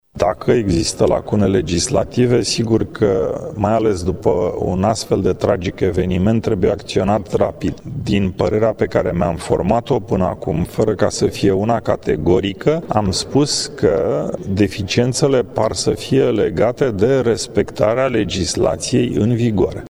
Preşedintele Senatului, Călin Popescu Tăriceanu, spune că parlamentarii vor acţiona cu promptitudine în cazul în care va fi nevoie de modificarea legislaţiei privind organizarea şi funcţionarea cluburilor.